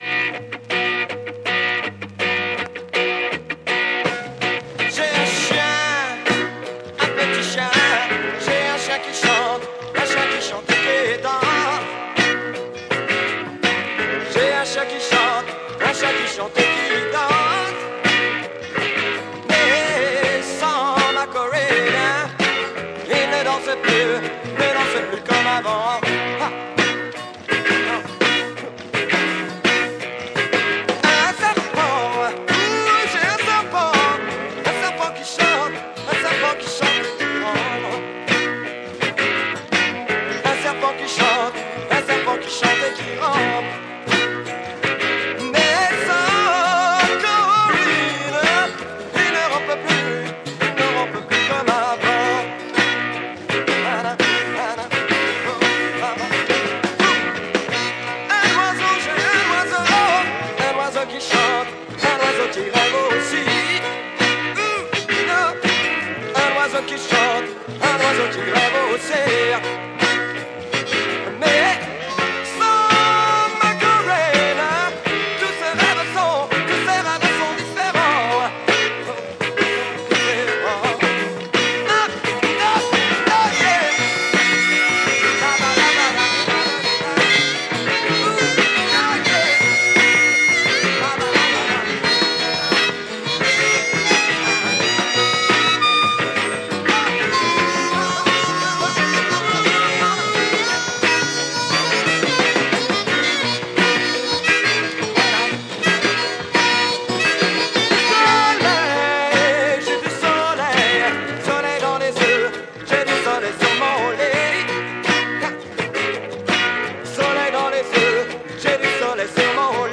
French Beat Soul single
French Beaty pop-soul.